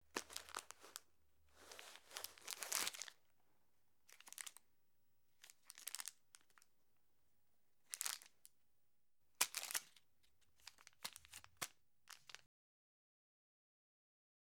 Plastic Bag Movement and Crinkle Sound
household